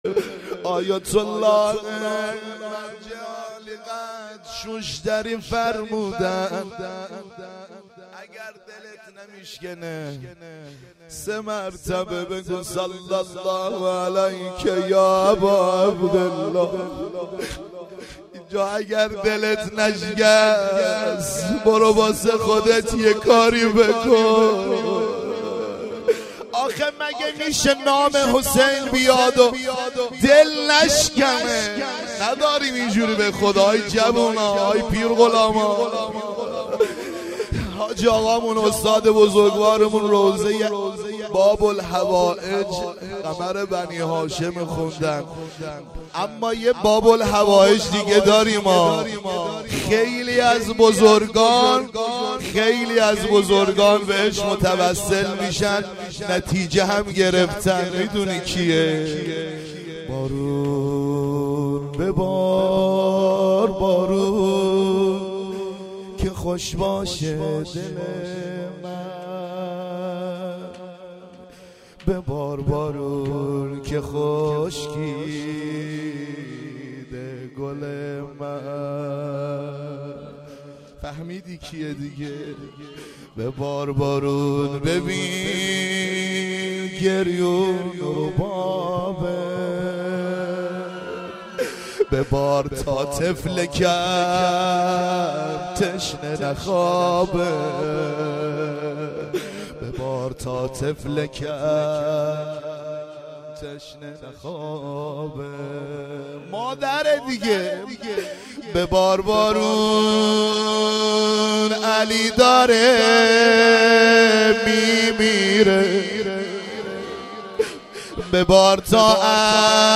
مراسم هفتگی مجمع رهروان حضرت زینب س